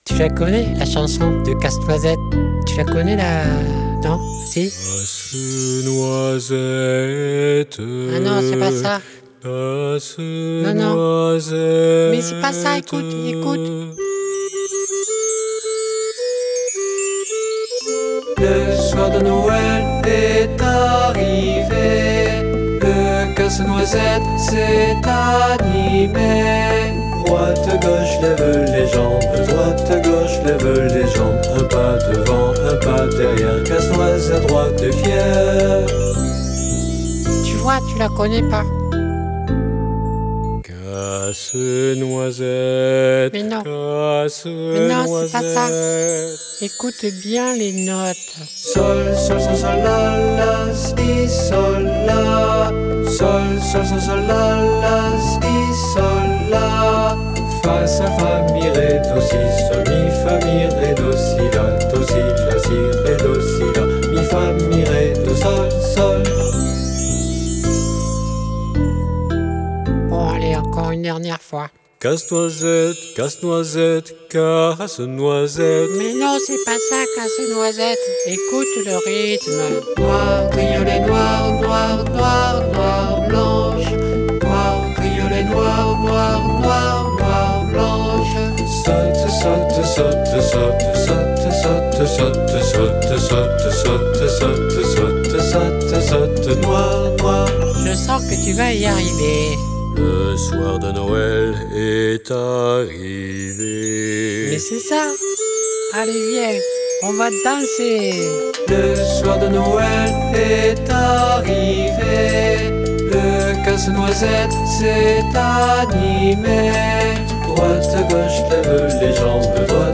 Audio conte et chansons pour enfants